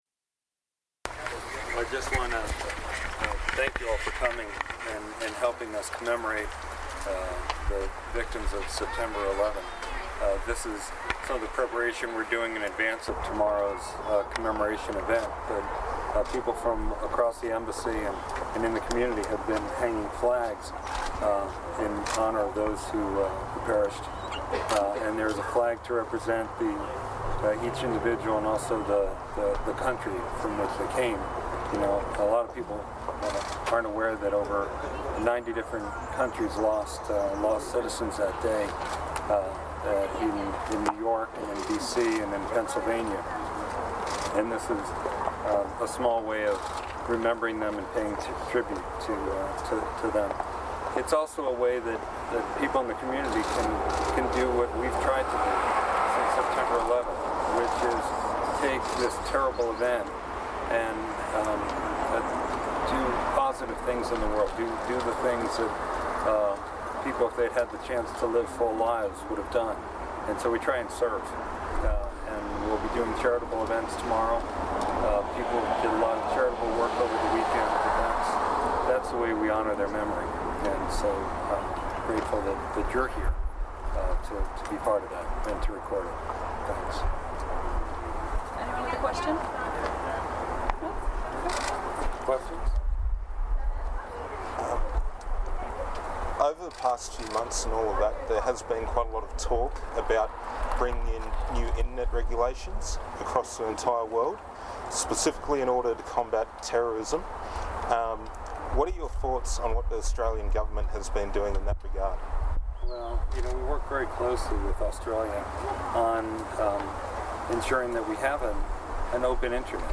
Ambassador Bleich reflecting on the victims of 9/11 at the US Embassy in Canberra (10-9-12)
Press Conference and flag-planting ceremony --